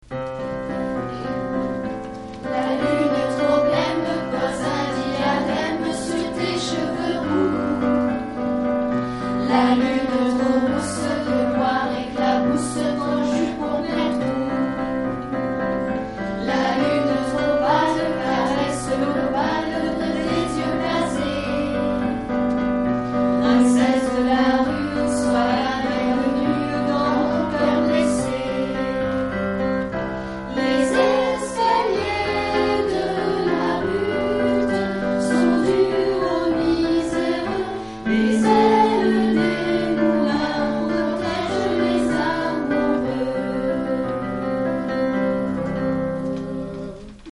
Chorale
Extraits audios de nos r�p�titions pour le spectacle 2006 : � La complainte de la butte